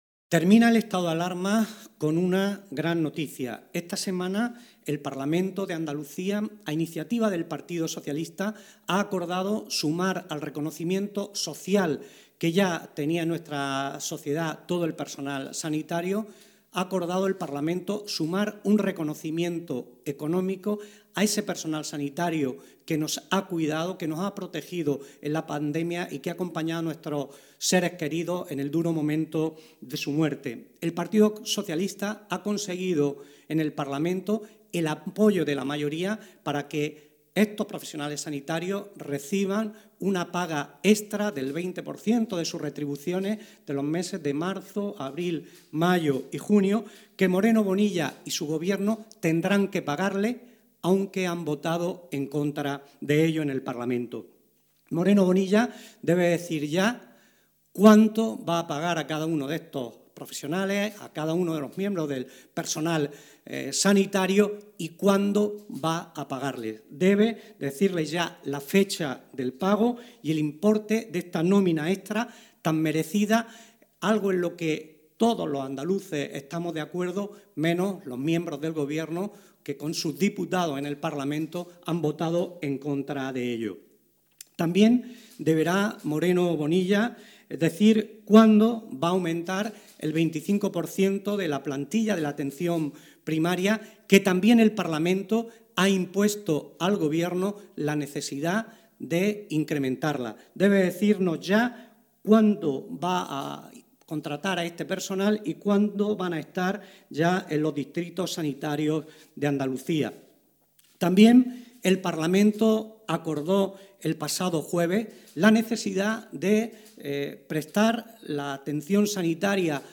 José-Luis-Sánchez-Teruel-en-rueda-de-prensa-de-sanidad